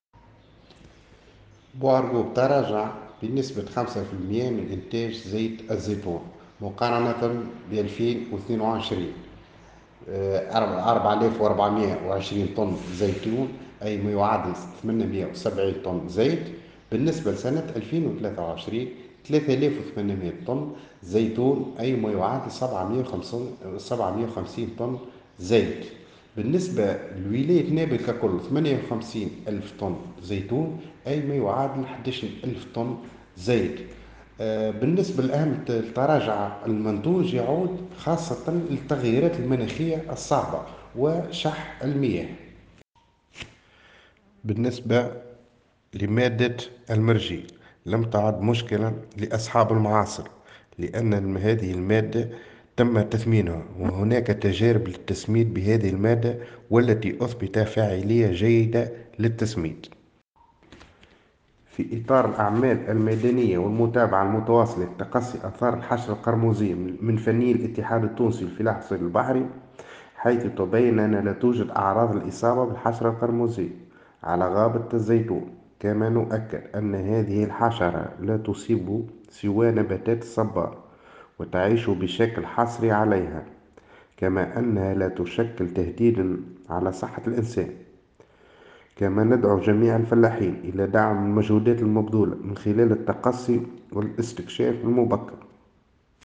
Cap Bon: La production des olives en baisse [Déclaration]